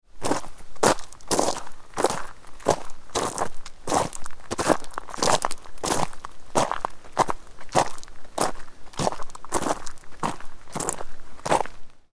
Sharp-gravel-footsteps-sound-effect.mp3